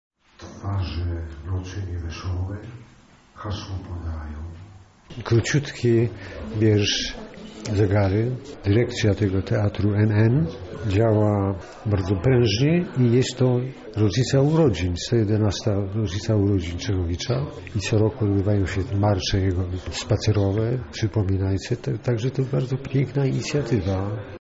Jak co roku 15 marca Teatr NN przygotował niecodzienne czytanie „Poematu o mieście Lublinie”.
Czytanie Czechowicza
Czytanie-Czechowicza.mp3